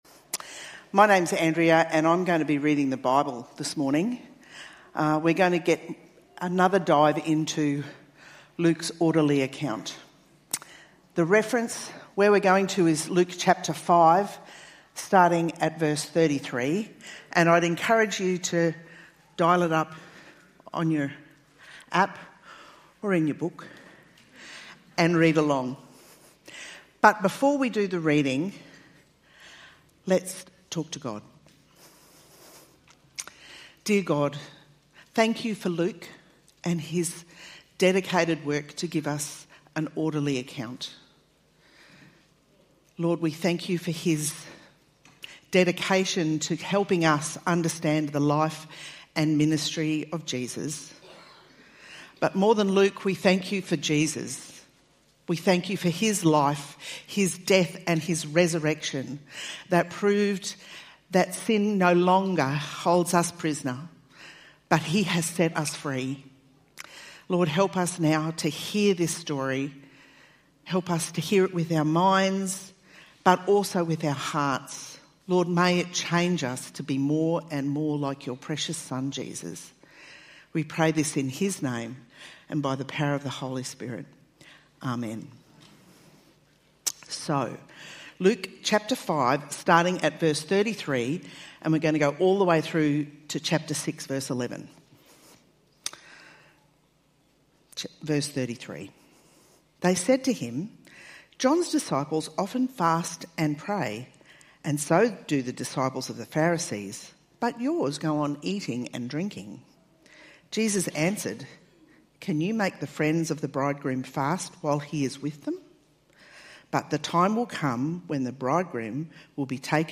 Living Church Online Service I Am the Bread of Life | 24th May 2020 - Living Church